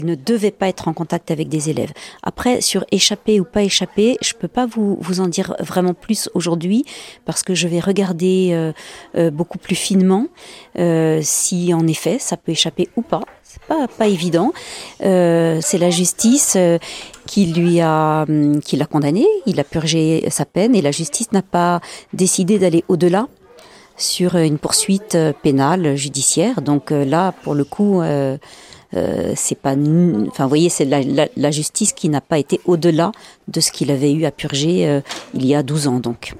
Comment cet homme condamné pour de tels faits a pu continuer à travailler au sein de l’Education nationale et comment cela a pu échapper aux autorités compétentes ? Carole Drucker-Godard, rectrice de l’Académie de Montpellier.